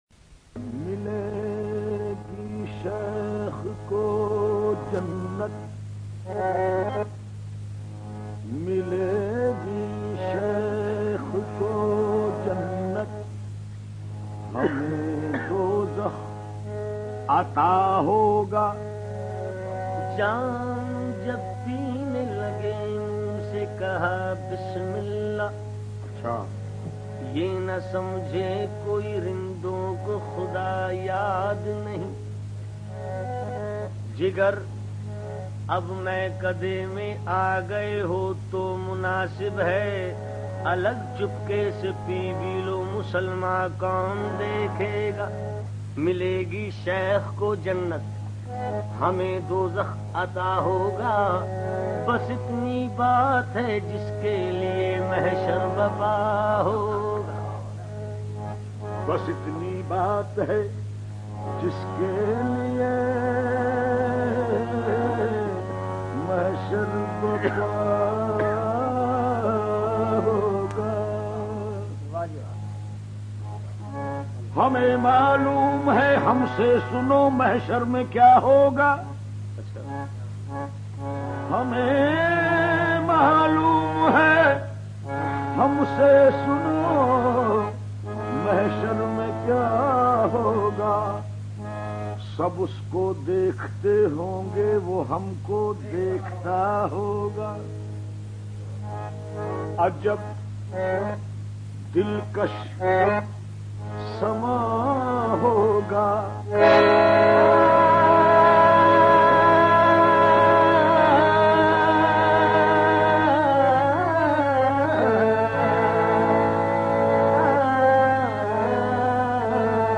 Urdu Ghazal